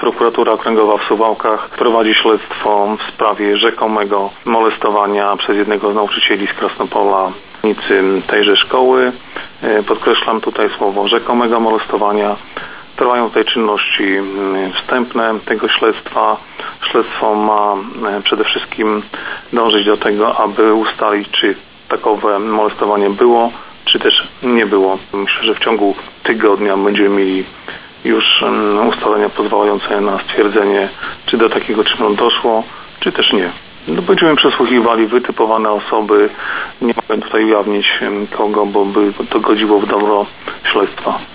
Śledczy przesłuchują wytypowane osoby i próbują ustalić czy nauczyciel dopuścił się zabronionego czynu. Szczegóły przedstawił